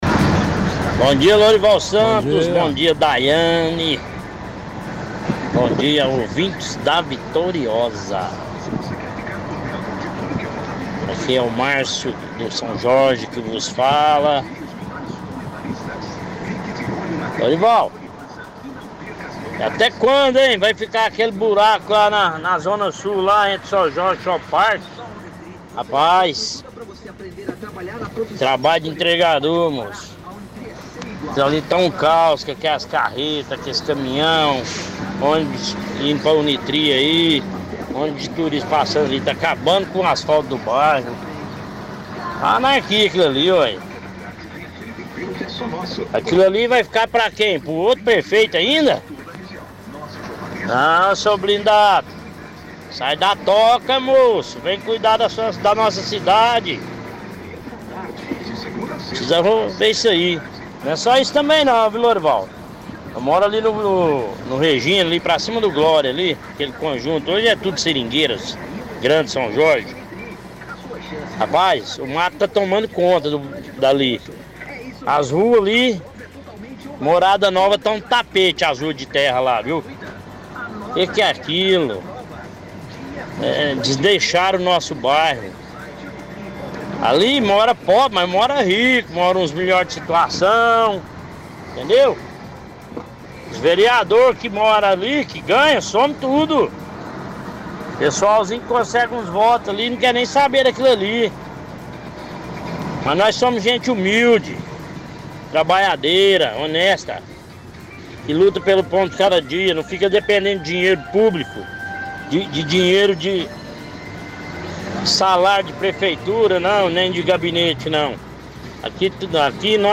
– Ouvinte questiona até quando ficará a cratera no anel viário sul.